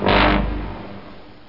Bone Pedal Sound Effect
bone-pedal.mp3